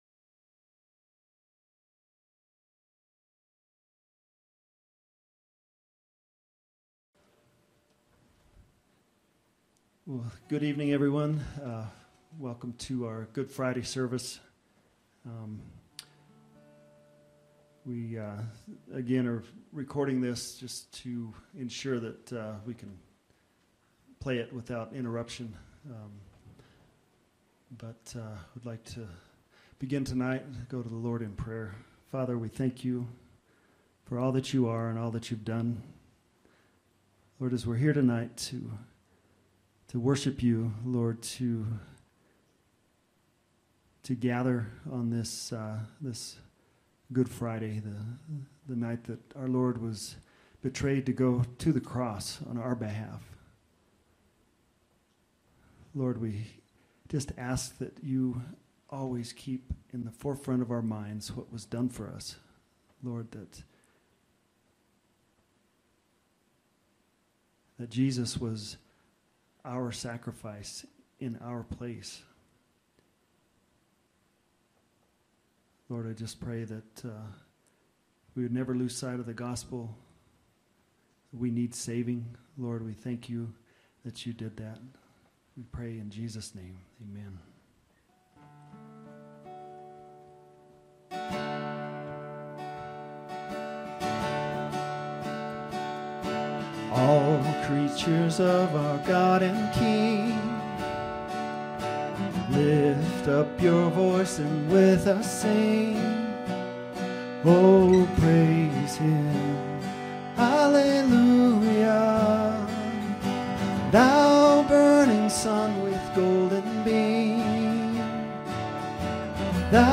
Good Friday Worship Service